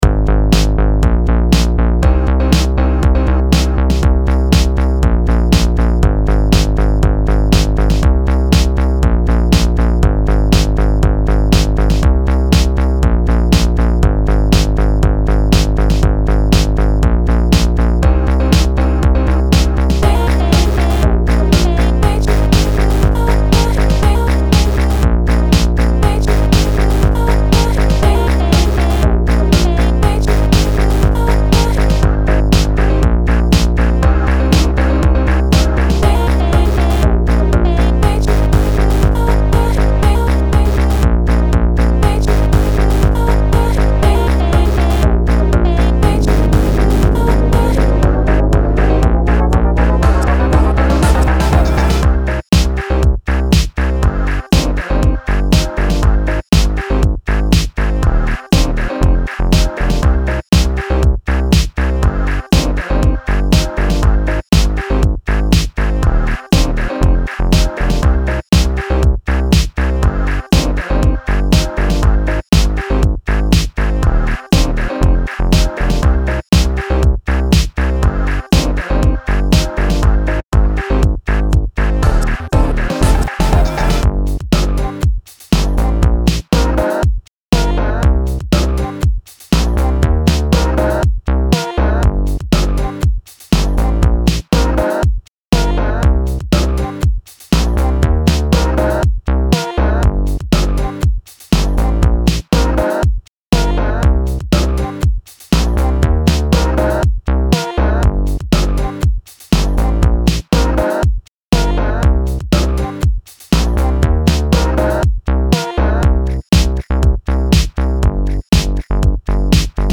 This is a huge collage made entirely from samples.